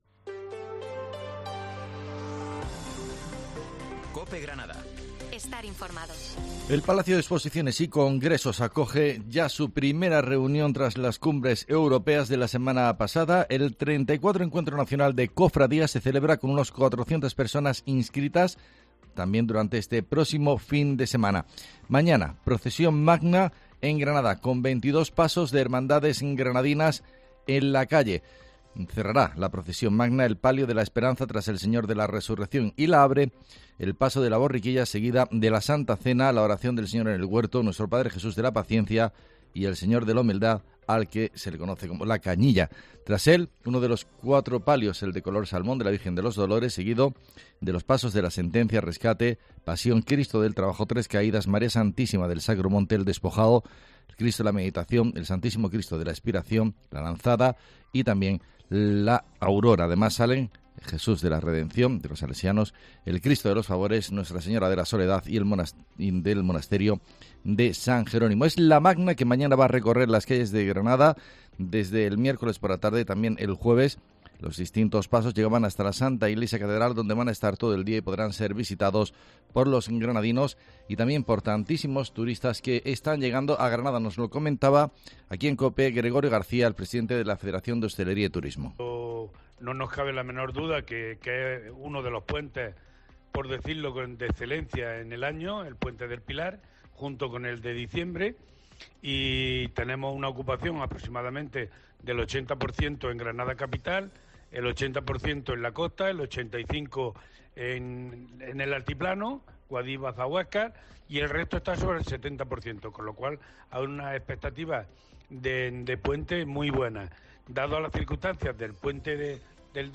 Herrera en Cope Granada, informativo del 13 de octubre